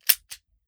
38 SPL Revolver - Dry Trigger 004.wav